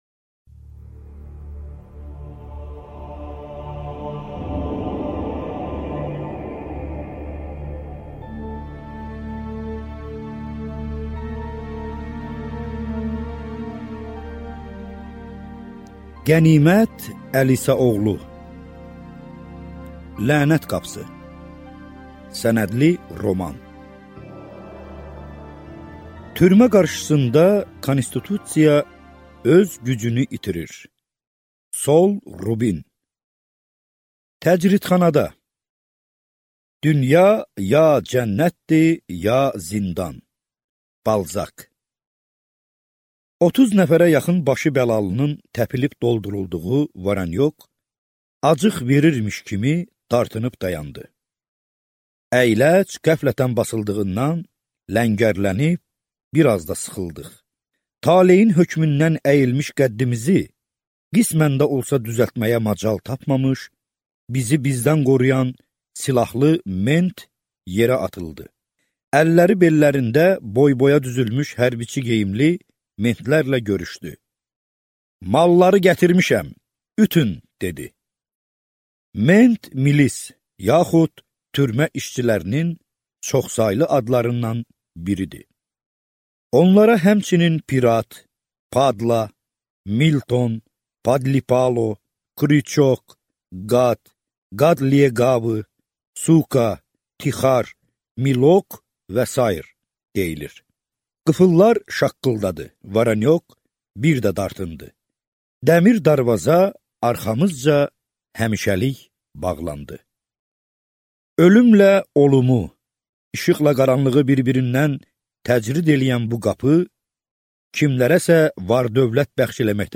Аудиокнига Lənət qapısı | Библиотека аудиокниг
Прослушать и бесплатно скачать фрагмент аудиокниги